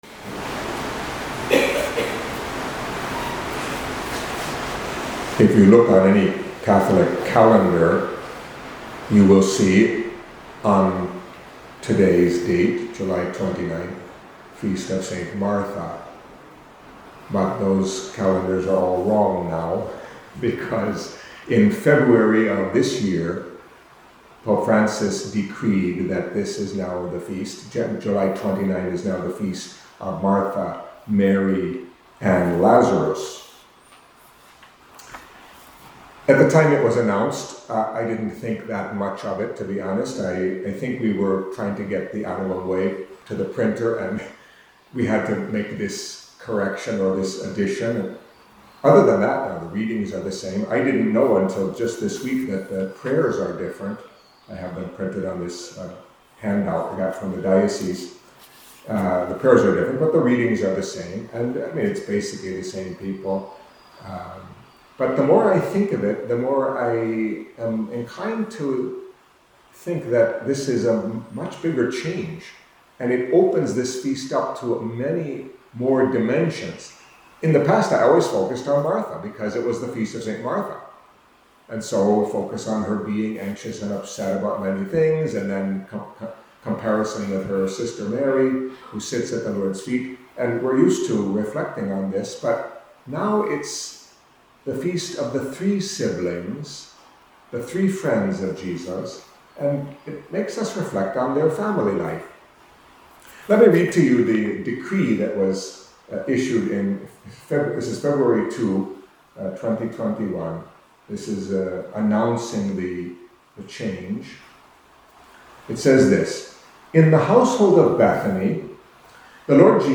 Catholic Mass homily for the Feast of Saints Martha, Mary and Lazarus